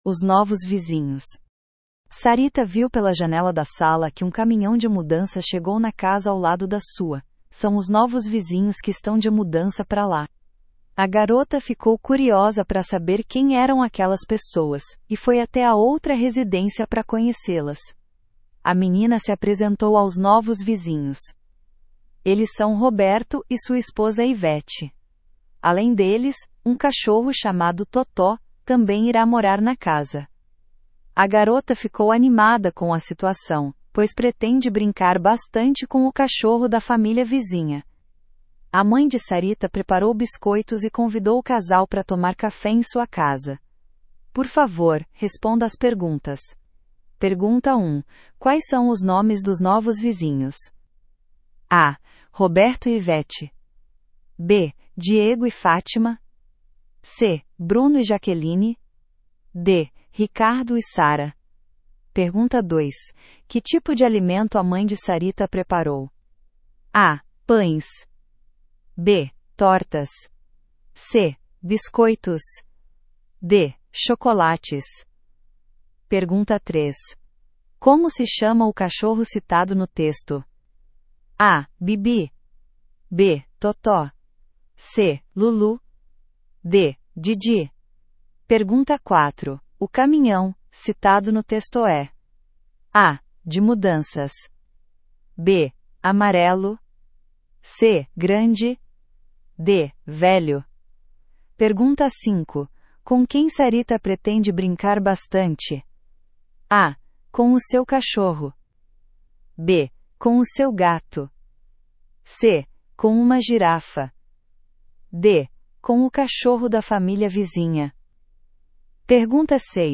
Brasile